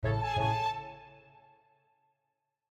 Plays short end of the track